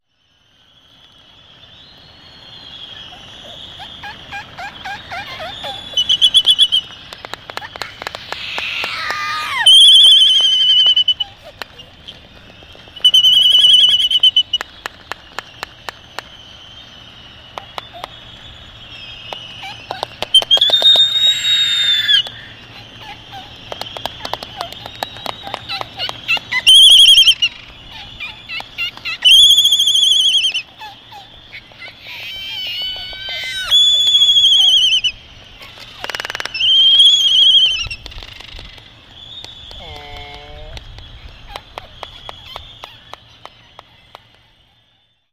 • laysan albatrosses.wav
laysan_albatrosses_0Yw.wav